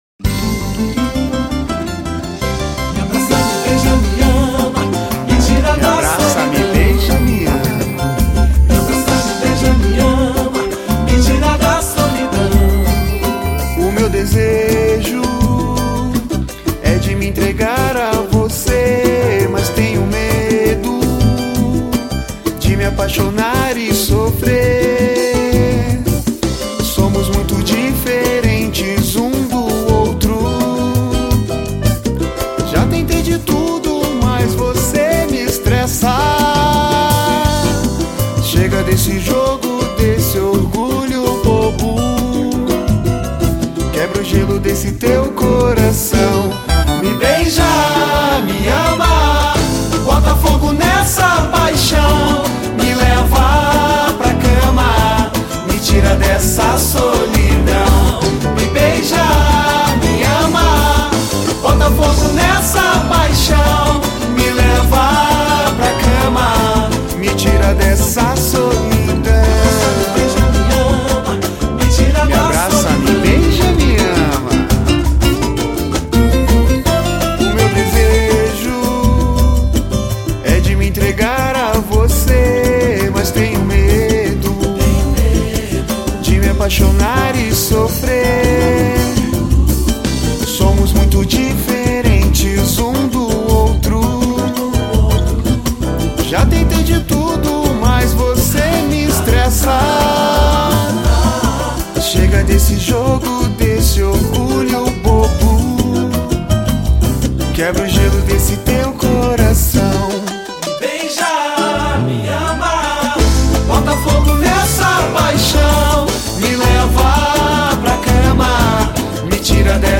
EstiloPagode